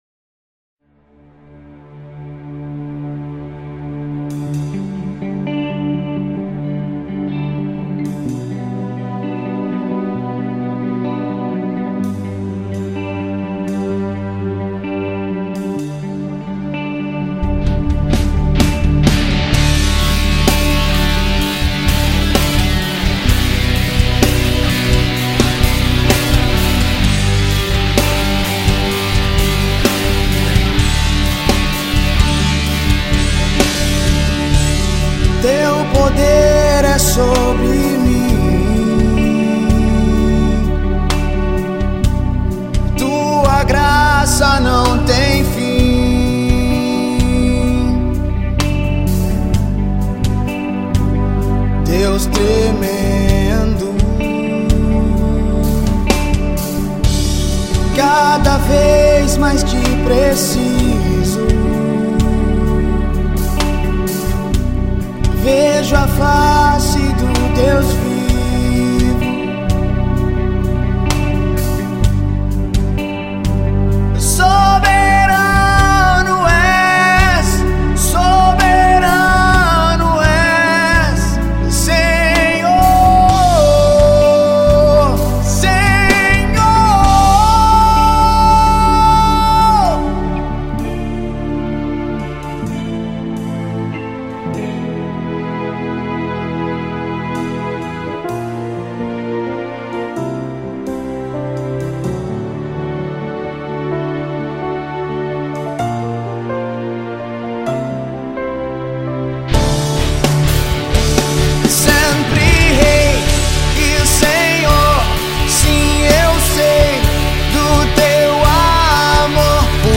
EstiloGospel